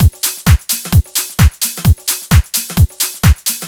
Hat Shuffle 02.wav